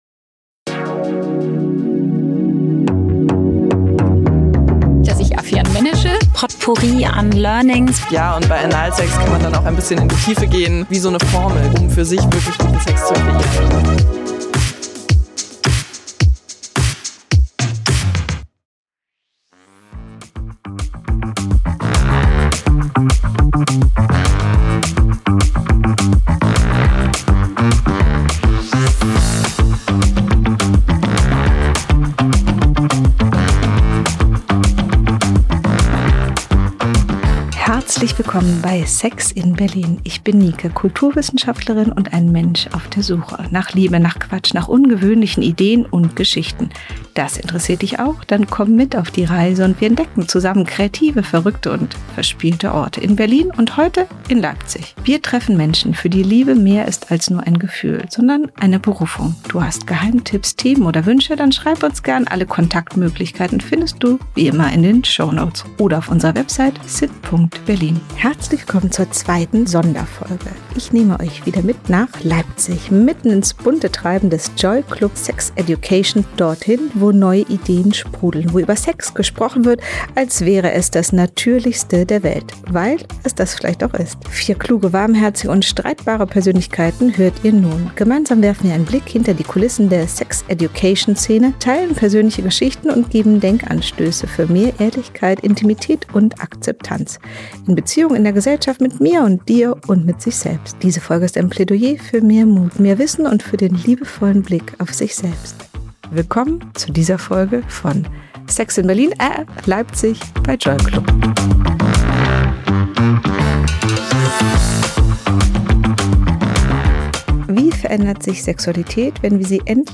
In der zweiten Folge vom JOYclub-Sex-Education-Event geht es direkt ans Eingemachte: Warum tun wir uns oft so schwer, ehrlich über Sex zu sprechen?...